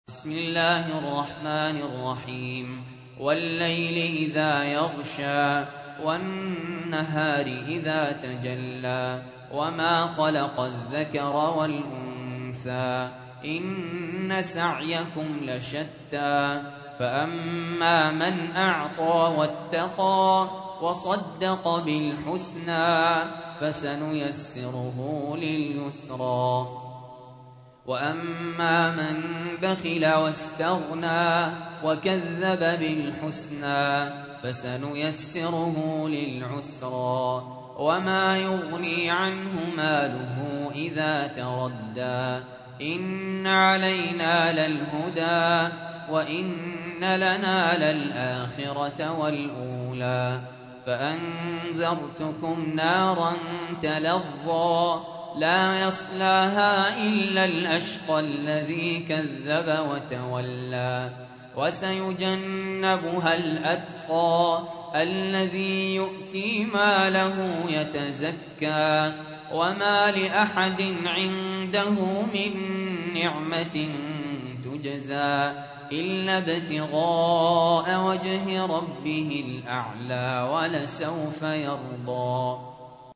تحميل : 92. سورة الليل / القارئ سهل ياسين / القرآن الكريم / موقع يا حسين